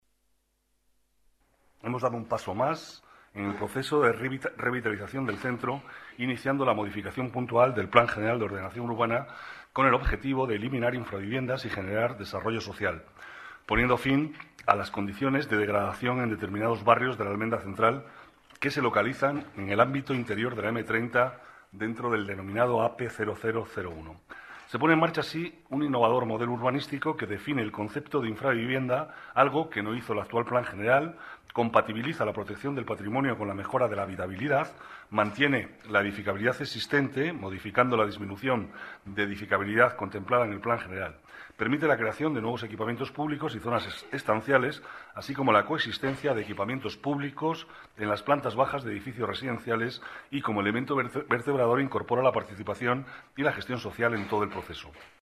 Nueva ventana:Declaraciones de Manuel Cobo